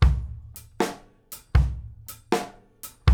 GROOVE 5 04L.wav